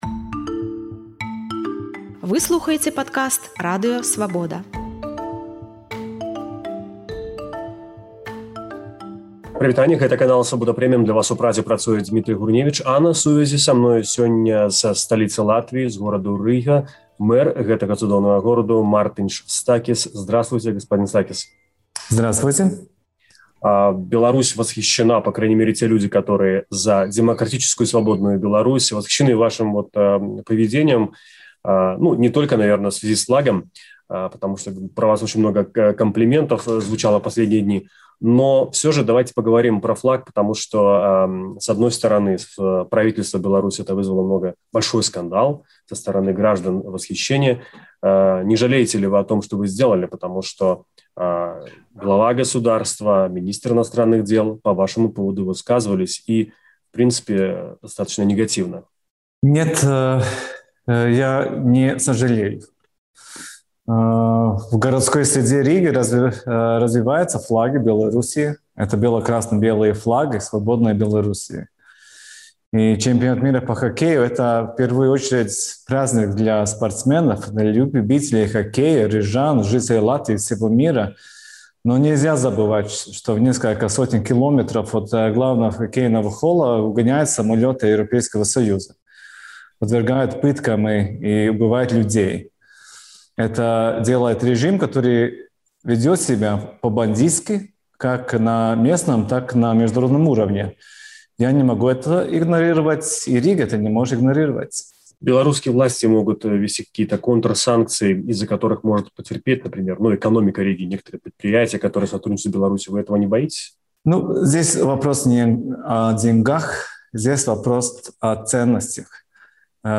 У інтэрвію «Свабодзе» мэр Рыгі Мартыньш Стакіс расказаў пра тое, ці шкадуе пра вывешваньне бел-чырвонага-белага сьцягу ў цэнтры сталіцы, пра рэакцыю на свой учынак, ці баіцца санкцый з боку афіцыйнага Менску і што раіць беларусам з латыскага досьведу дэмакратыі.